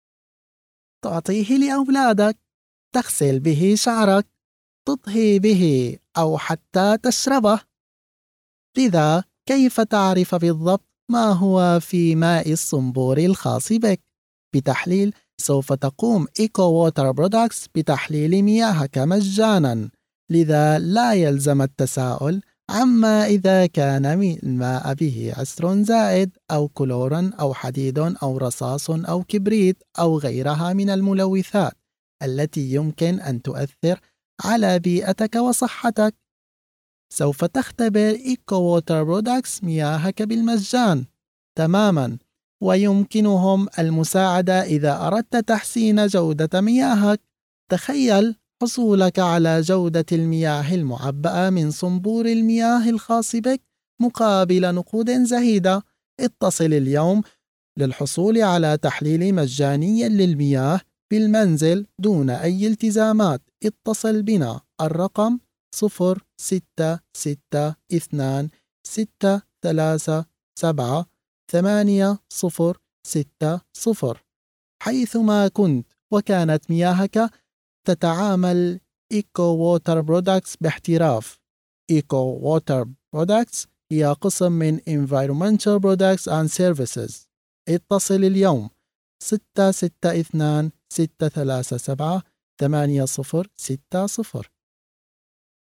Arabic voiceover test1 - EQHO
Arabic Male 03811
NARRATION
COMMERCIAL